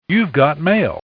Play, download and share YOU GOT MAIL original sound button!!!!